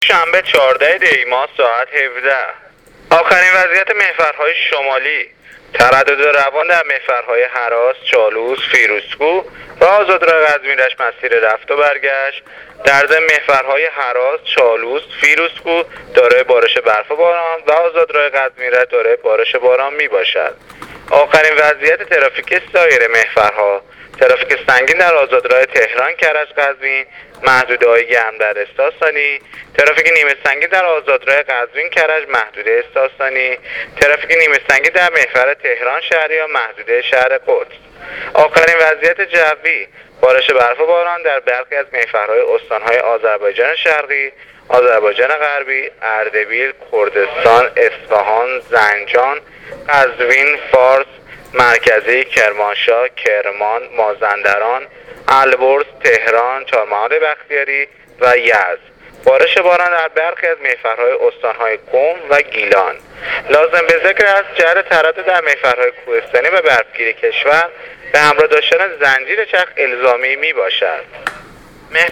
گزارش رادیو اینترنتی از آخرین وضعیت ترافیکی جاده‌ها تا ساعت ۱۷ چهاردهم دی ۱۳۹۸: